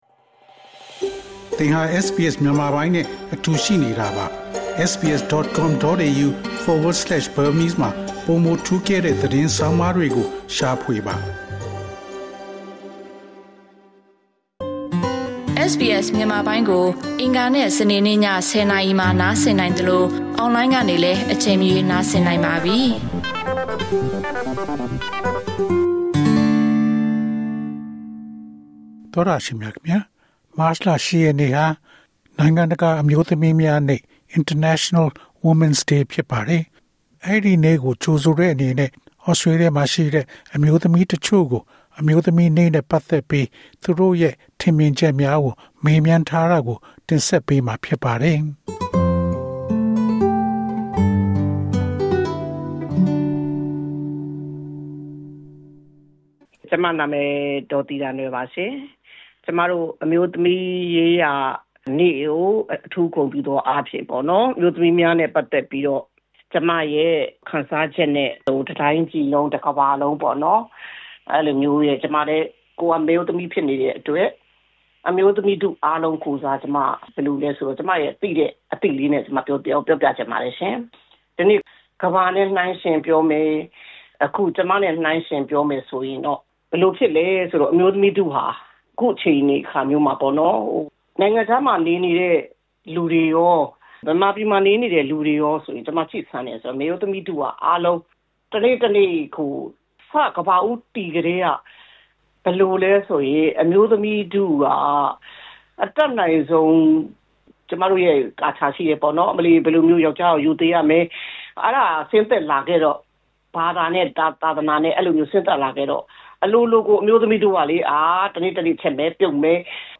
အပြည်ပြည်ဆိုင်ရာ အမျိုးသမီးများနေ့အတွက် မြန်မာအမျိုးသမီးများကို မေးမြန်းထားခန်း